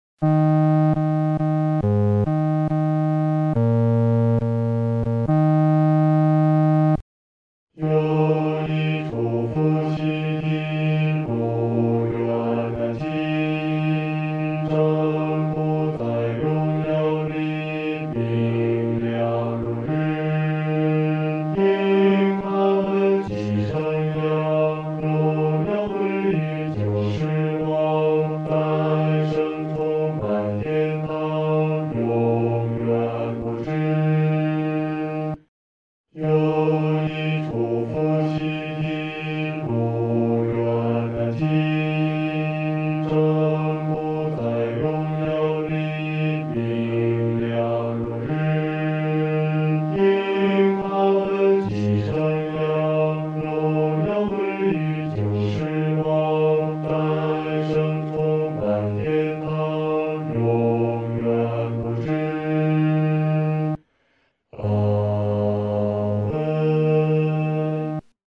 合唱
四声 下载
本首圣诗由网上圣诗班 (青草地）录制
这首诗歌可用从容的中速来弹唱。